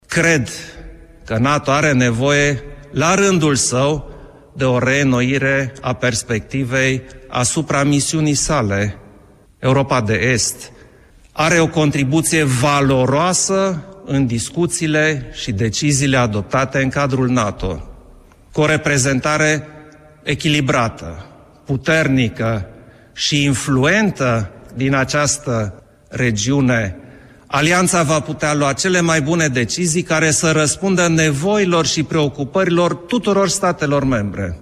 Şeful statului a vorbit despre o nouă perspectivă a Alianţei şi de importanţa Europei de Est în realizarea acestei perspective.